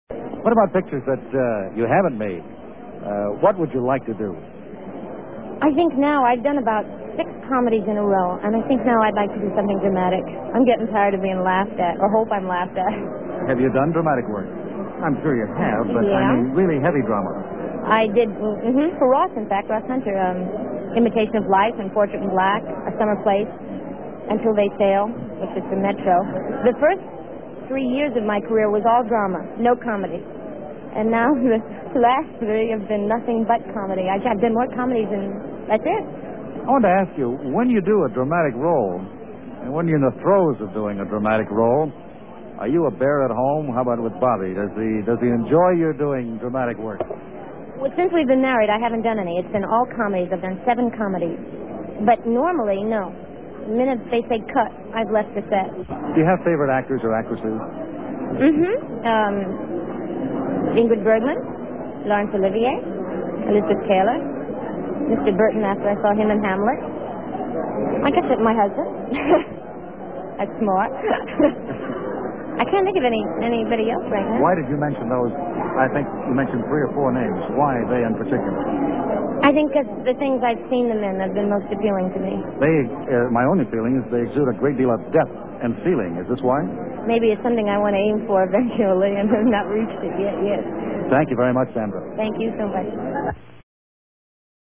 In this episode of Hollywood Backstage, saluting Universal Studios, Sandra appeared at a party (Circa 1965) in Universal Studios commissary announcing the signing of producer Ross Hunter, a former Cleveland school teacher, to the biggest exclusive contract at the time ($75 million) for the next 7 years.
Small segment of interview.